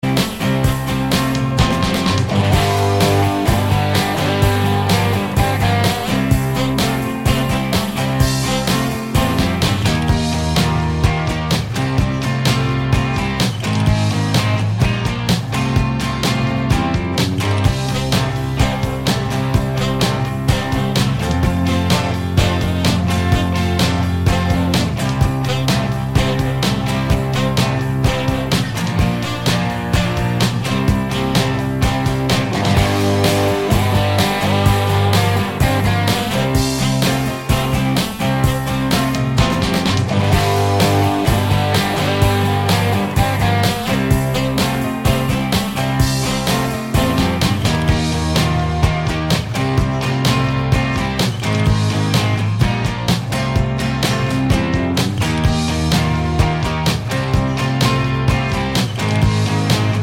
Minus Sax Solo and No Backing Vocals Rock 3:51 Buy £1.50